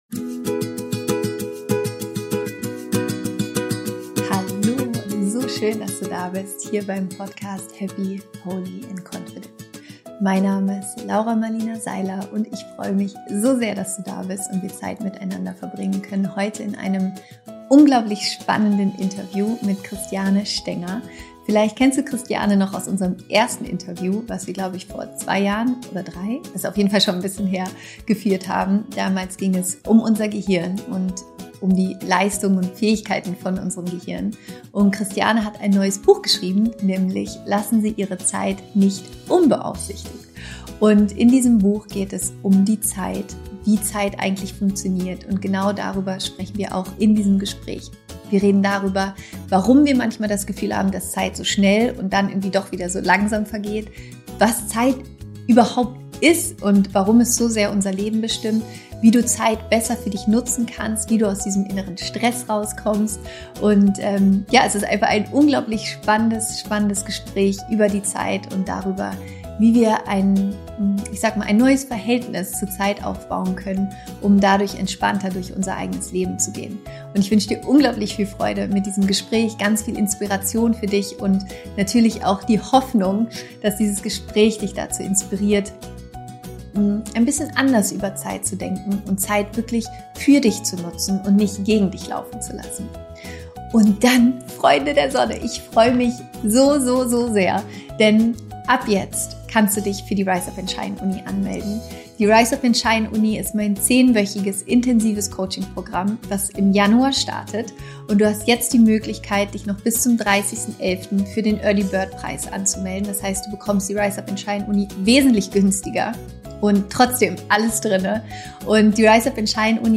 Du willst mehr Zeit im Leben? - Interview Special mit Christiane Stenger